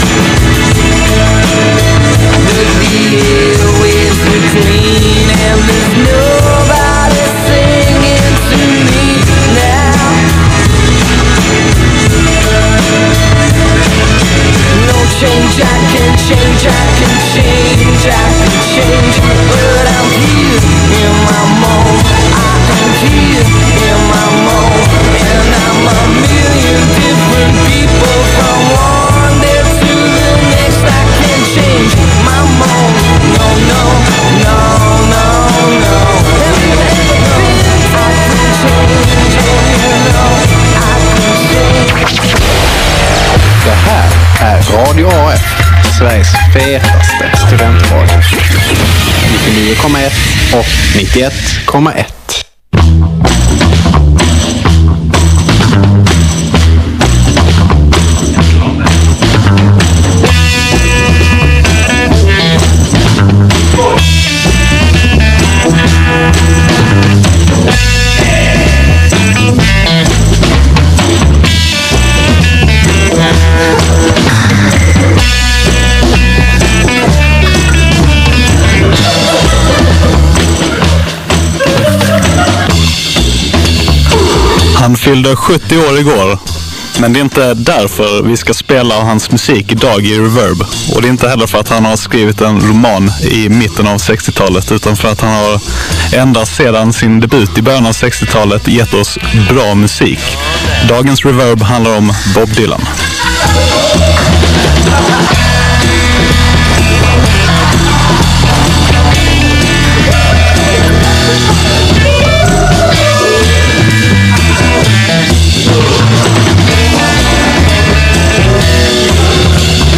I dagens Reverb duckar vi de allra största hitsen, spelar lite mer udda favoriter och pratar fritt om legendens liv och verk.